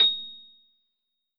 piano-ff-66.wav